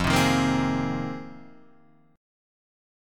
Fm11 chord